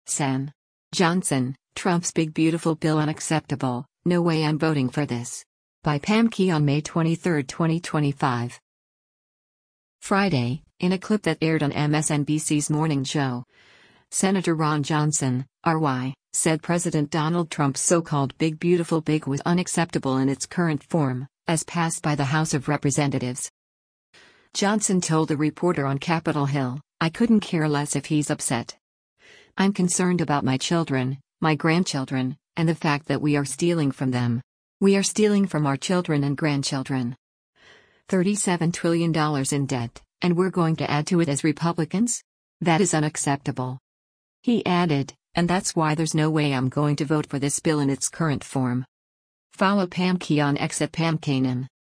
Friday, in a clip that aired on MSNBC’s “Morning Joe,” Sen. Ron Johnson (R-WI) said President Donald Trump’s so-called “Big Beautiful Big” was unacceptable in its current form, as passed by the House of Representatives.